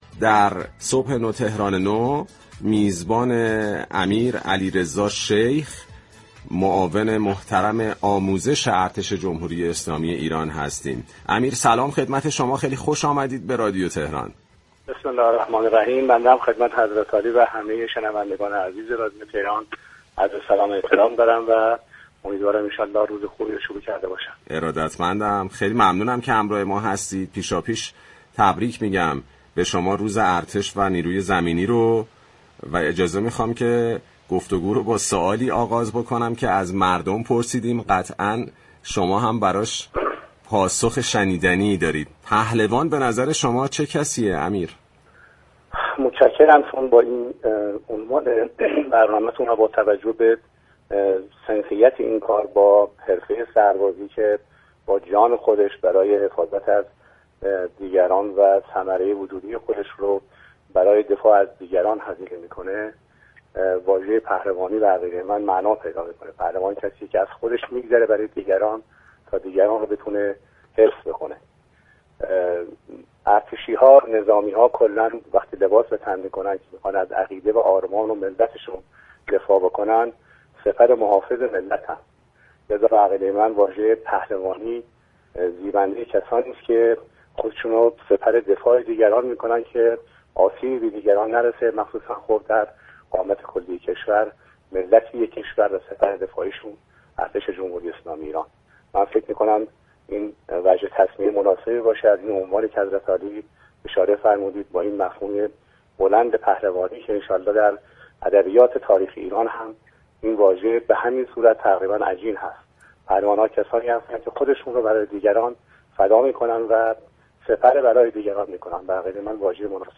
معاون آموزش ارتش جمهوری اسلامی ایران در گفت و گو با رادیو تهران تاكید كرد: بخشی از توان دفاعی كشور در رژه روز ارتش به نمایش گذاشته و
به گزارش پایگاه اطلاع رسانی رادیو تهران،امیر علیرضا شیخ معاون آموزش ارتش جمهوری اسلامی ایران در ارتباط زنده تلفنی با برنامه "صبح نو، تهران نو" با بیان این كه پهلوانی زیبنده افرادی است خود را سپر دفاع دیگران می‌كنند تا آسیبی به آن‌ها نرسد، گفت: سپر دفاعی ملت و كشور، ارتش جمهوری اسلامی ایران است.